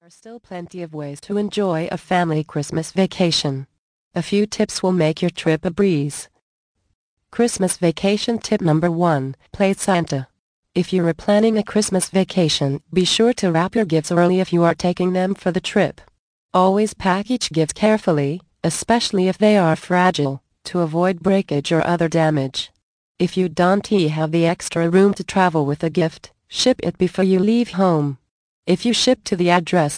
The Magic of Christmas audio book. Vol. 1 of 10 - 63min